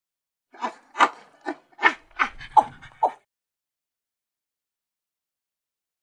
Monkey ( Unknown ) Panting. Heavy Panting, Whines And Barks. Close Perspective.